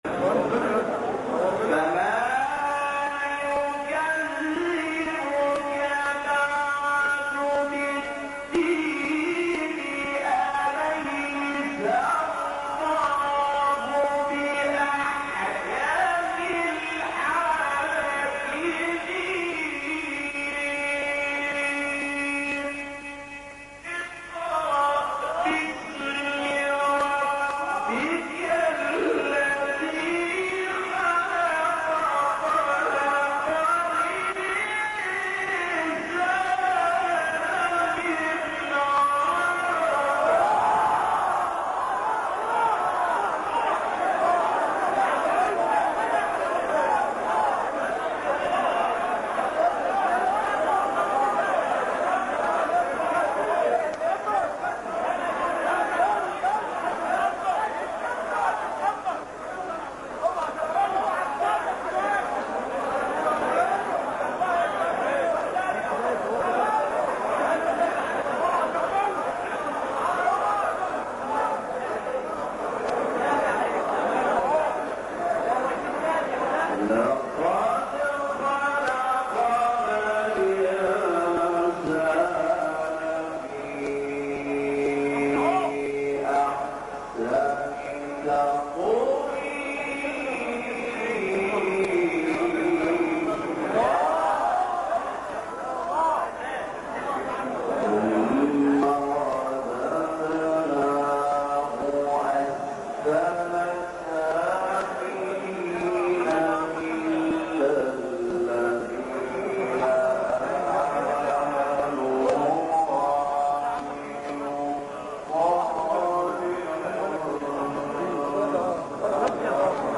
گروه شبکه اجتماعی: مقاطع صوتی از تلاوت‌های قاریان برجسته مصری را می‌شنوید.